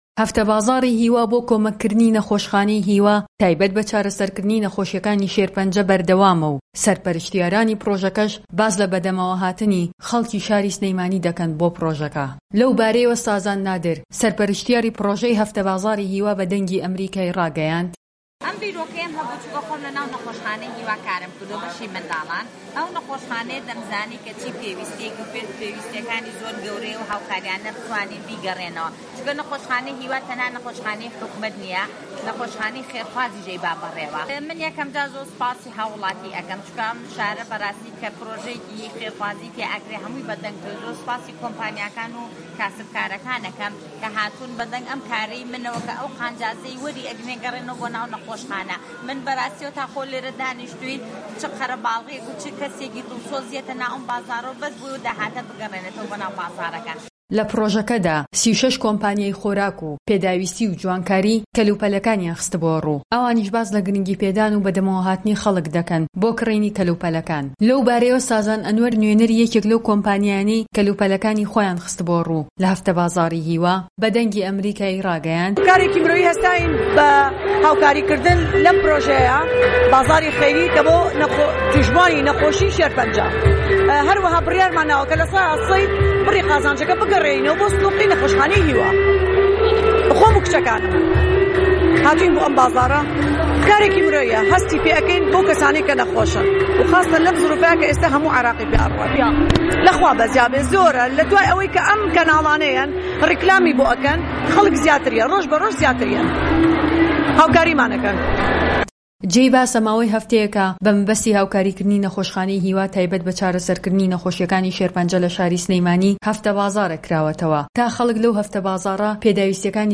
دەقی ڕاپۆرتەکەی پەیامنێرمان